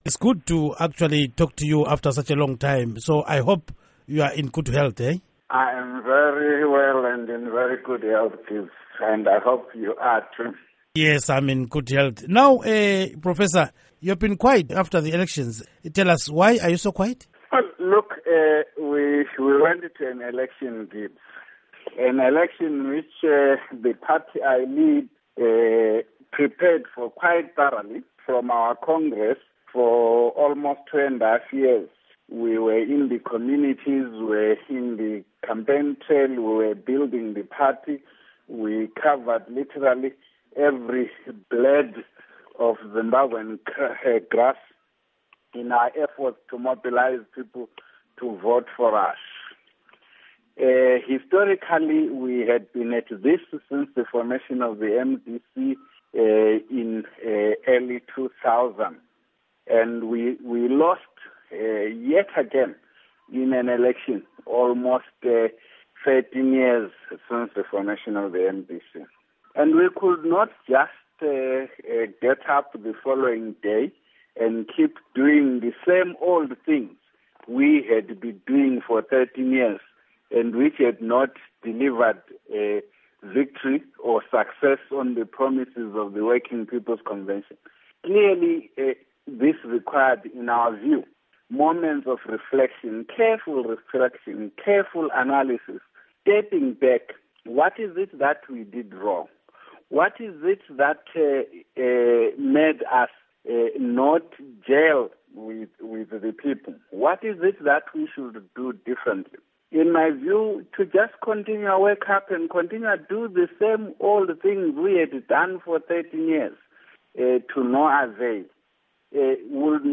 Interview With Welshman Ncube